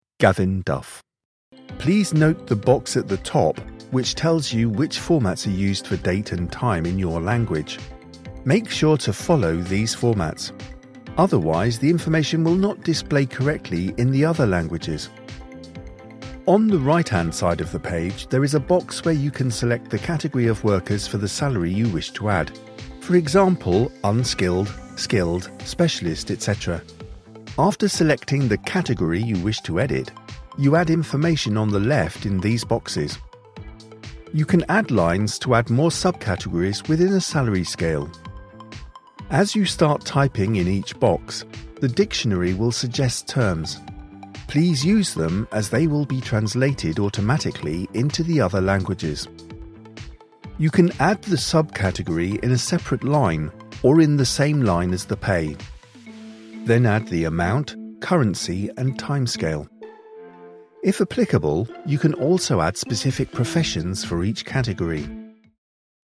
E-learning
I have a warm and engaging English RP accent which is suitable for a wide range of projects, including audiobook, corporate and commercial.
I produce audio from my purpose built home studio where I use a Shure SM7B mic with a Focusrite Scarlet 2i4 interface and Logic Pro on a Mac.
BaritoneBass